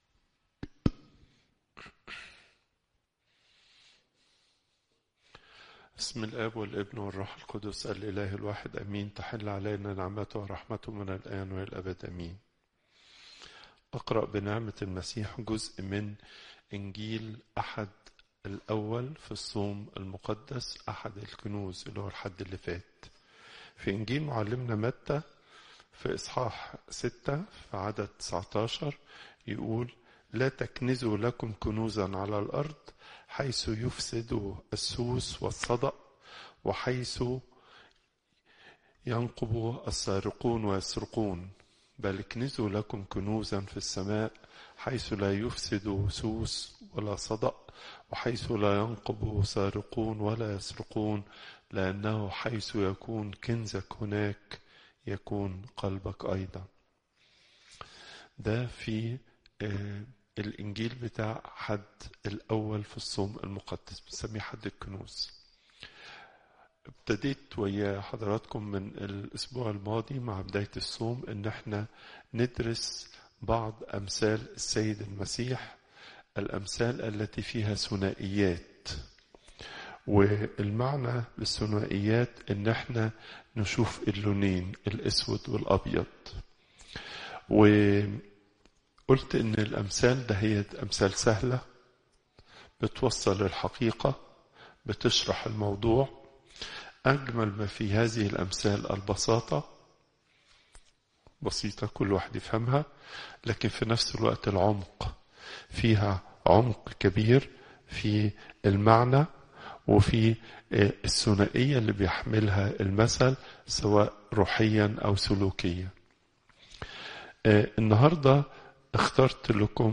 Popup Player تحميل الصوت البابا تواضروس الثانى الأربعاء، 05 مارس 2025 41:41 المحاضرة الأسبوعية لقداسة البابا تواضروس الثاني الزيارات: 258